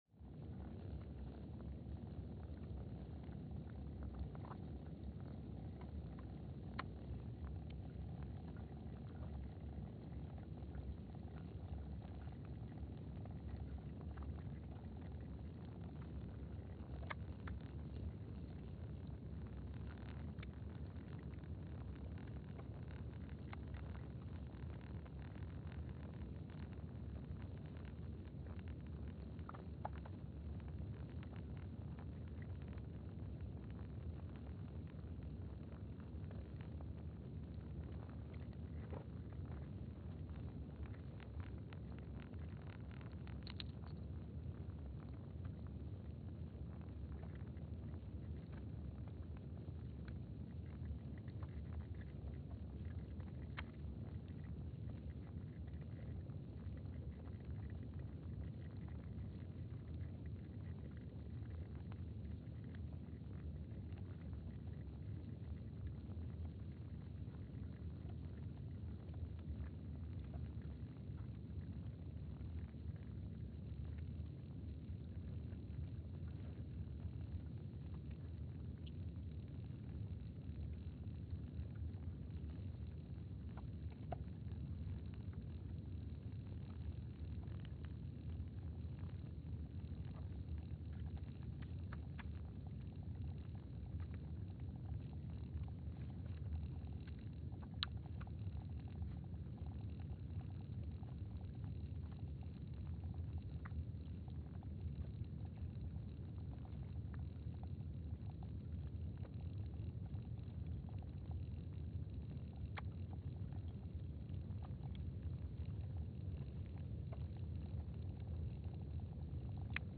Palmer Station, Antarctica (seismic) archived on May 29, 2025
Station : PMSA (network: IRIS/USGS) at Palmer Station, Antarctica
Sensor : STS-1VBB_w/E300
Speedup : ×500 (transposed up about 9 octaves)
Loop duration (audio) : 05:45 (stereo)
SoX post-processing : highpass -2 90 highpass -2 90